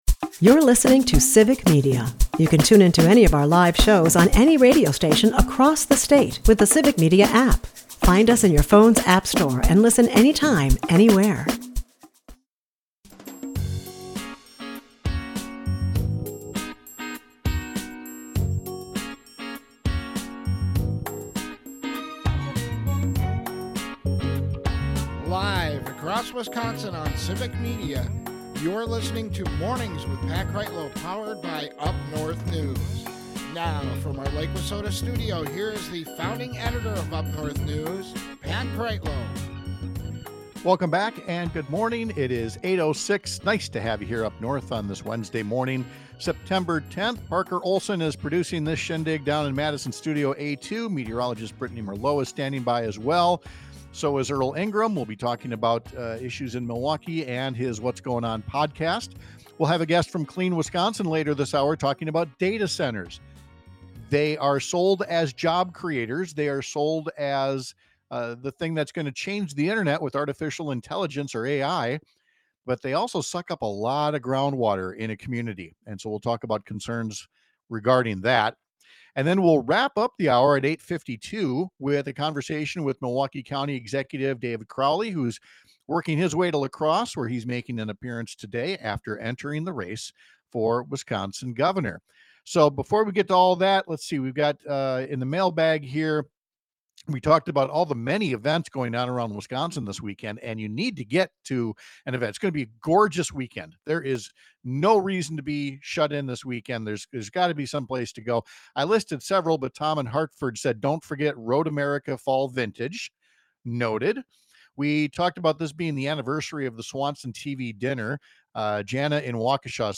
We’ll talk live with Milwaukee County Executive David Crowley, the most recent candidate to announce in the 2026 race for Wisconsin governor, about his decision to run and what he wants voters to know about his experience and positions.